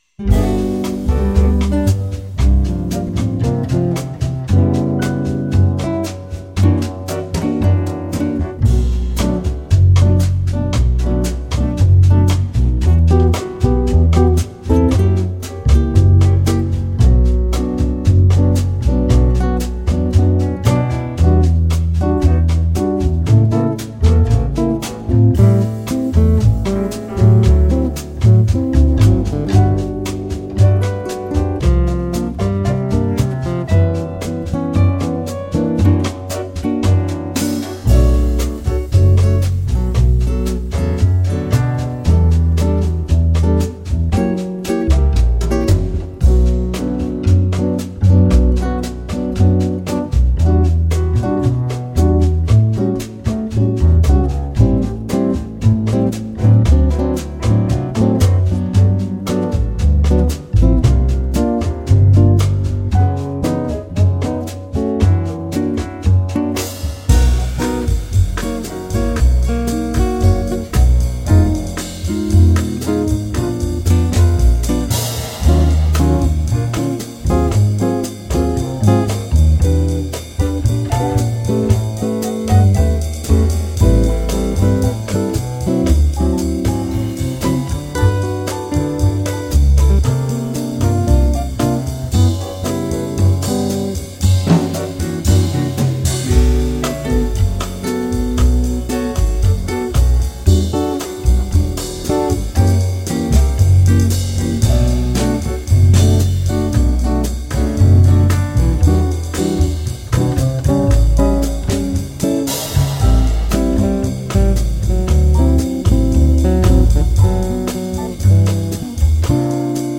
Mes Accomps